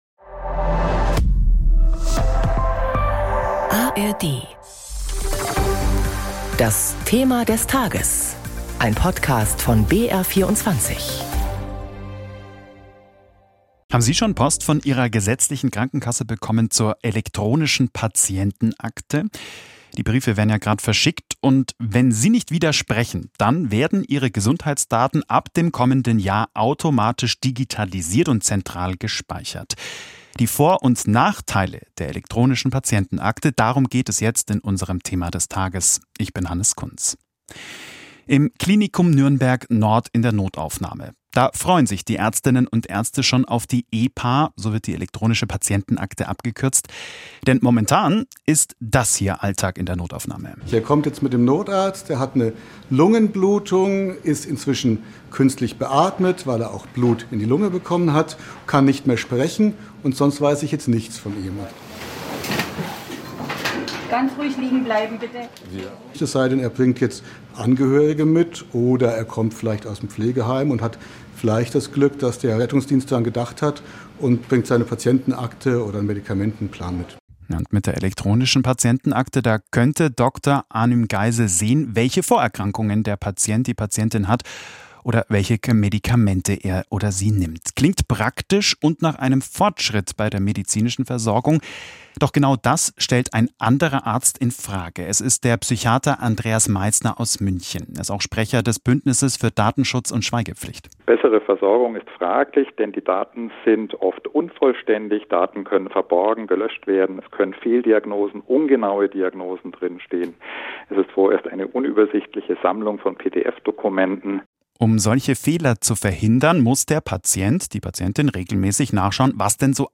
Interviews zu Pro- und Contra-Positionen